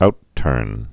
(outtûrn)